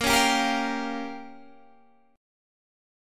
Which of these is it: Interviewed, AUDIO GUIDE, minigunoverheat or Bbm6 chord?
Bbm6 chord